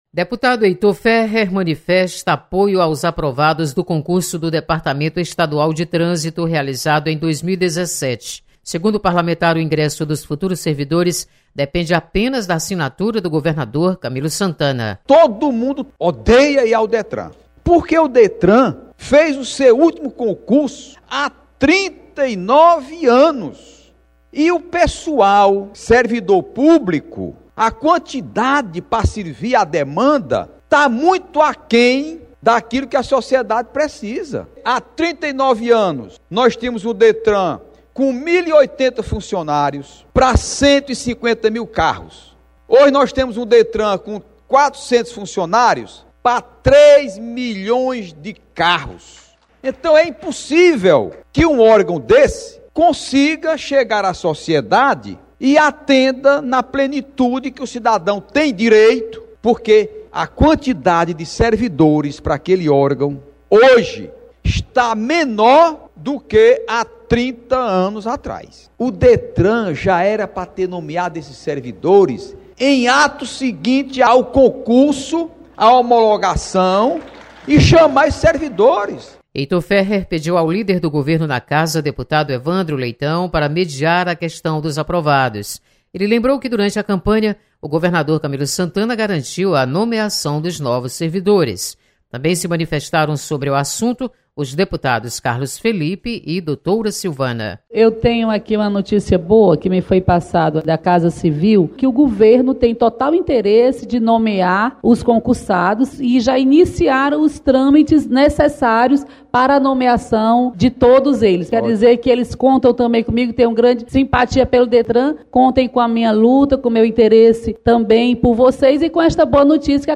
Deputado Heitor Férrer defende ingresso de concursados no Detran. Repórter